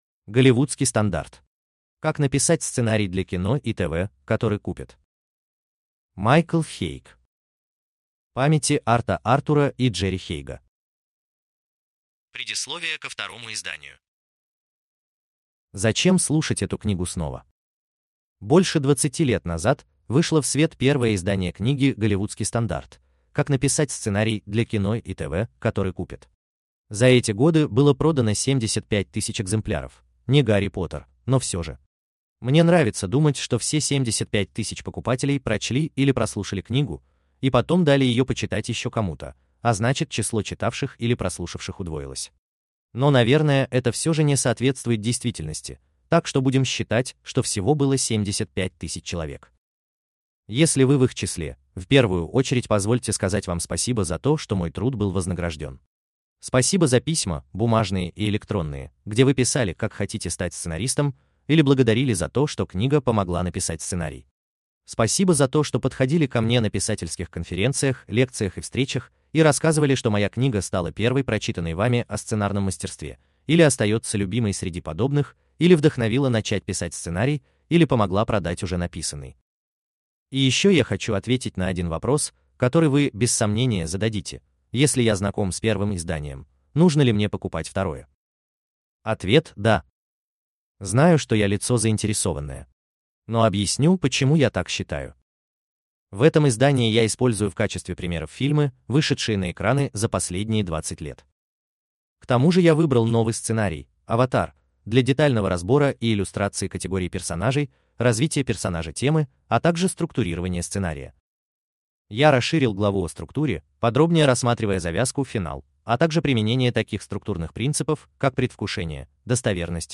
Аудиокнига Голливудский стандарт: Как написать сценарий для кино и ТВ, который купят | Библиотека аудиокниг